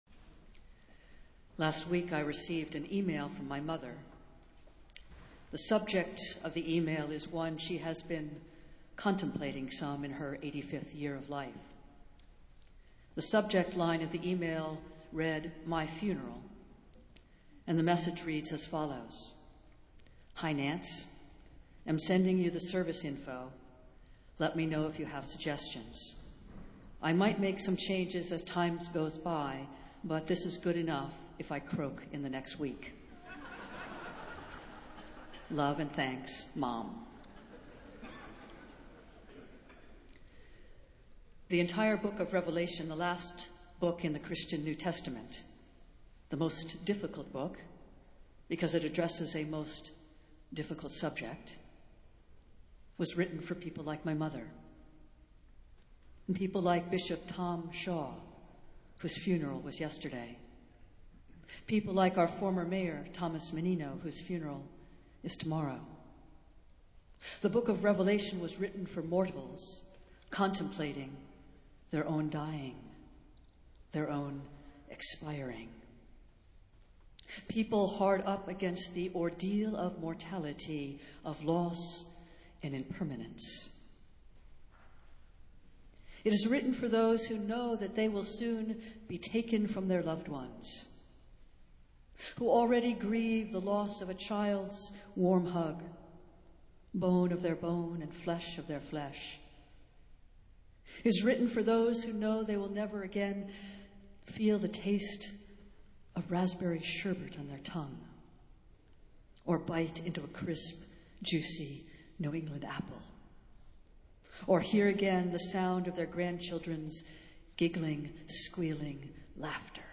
Festival Worship - All Saints' Sunday